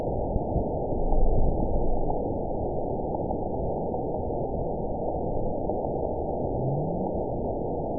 event 920405 date 03/23/24 time 22:17:08 GMT (1 month ago) score 9.64 location TSS-AB05 detected by nrw target species NRW annotations +NRW Spectrogram: Frequency (kHz) vs. Time (s) audio not available .wav